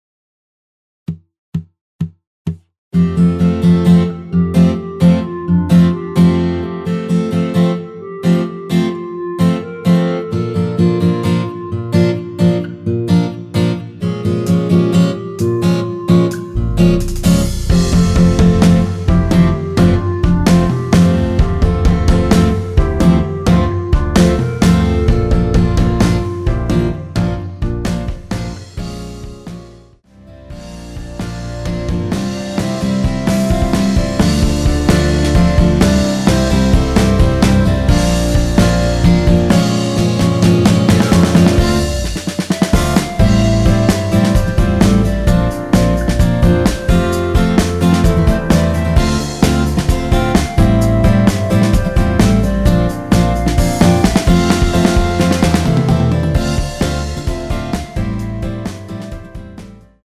전주 없이 시작하는 곡이라 4박 카운트 넣어 놓았습니다.(미리듣기 확인)
원키 멜로디 포함된 MR입니다.
앞부분30초, 뒷부분30초씩 편집해서 올려 드리고 있습니다.
중간에 음이 끈어지고 다시 나오는 이유는